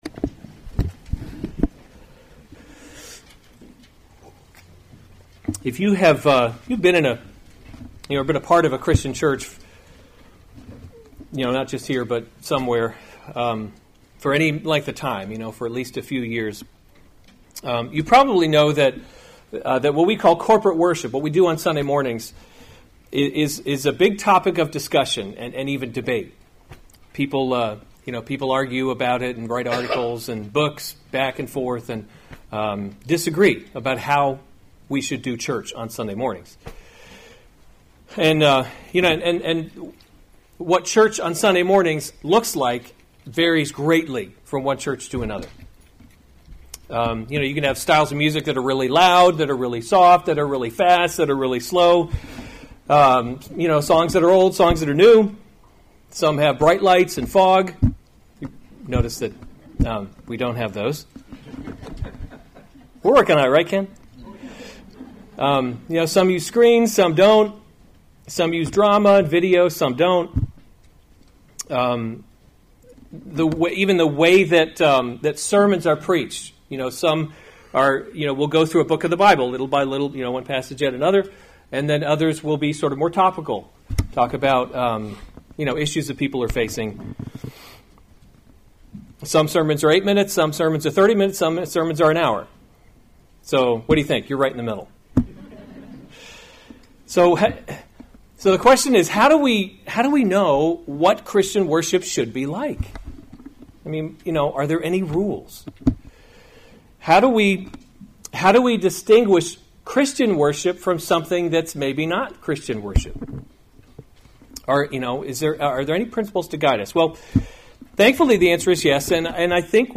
March 2, 2019 1 Kings – Leadership in a Broken World series Weekly Sunday Service Save/Download this sermon 1 Kings 12:25-33 Other sermons from 1 Kings Jeroboam’s Golden Calves 25 Then […]